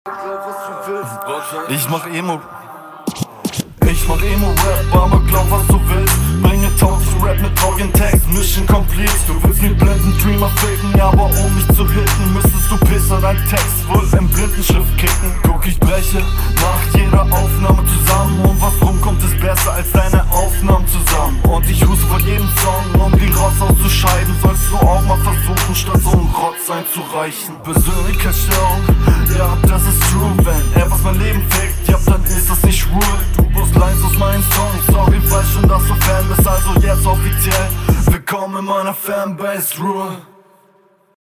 Mir gefällt der Flow hier richtig gut, der Stimmeinsatz passt auch voll gut zusammen, aber …